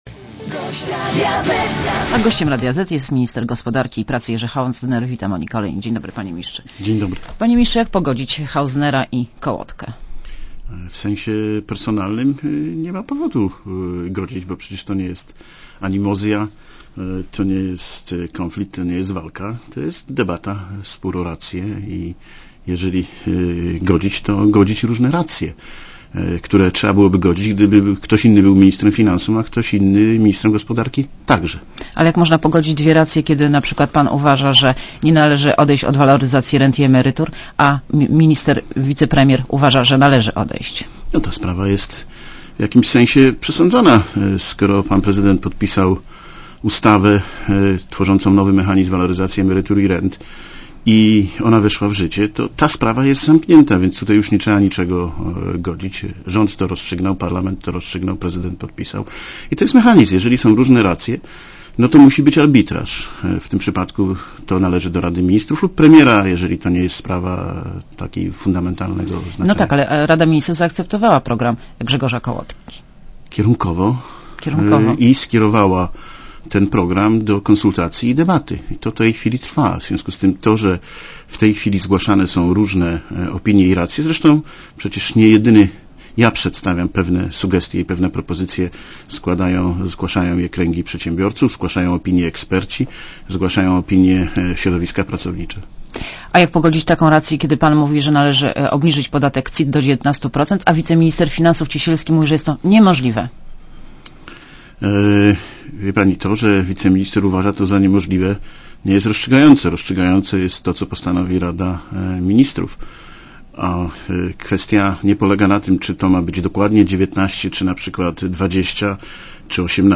(RadioZet) Źródło: (RadioZet) Posłuchaj wywiadu (2,5 MB) Panie Ministrze, jak pogodzić Hausnera i Kołodkę?